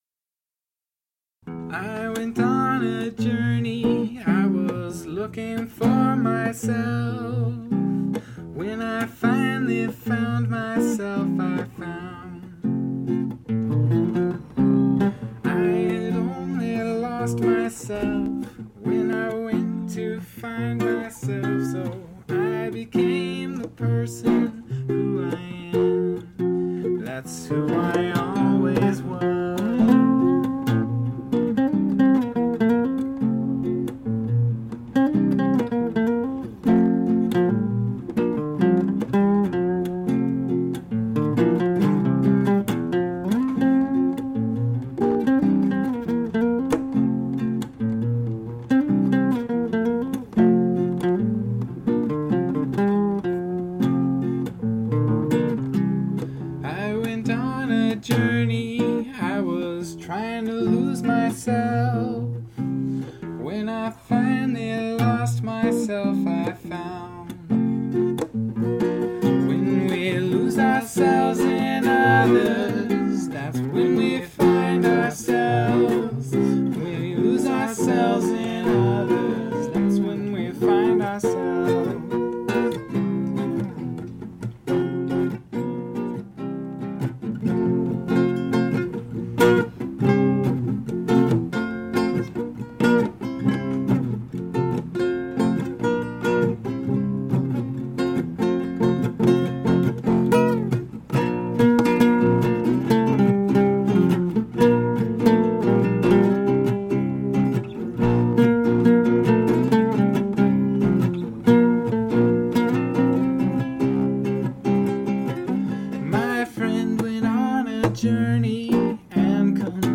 lost-and-found-sketch.mp3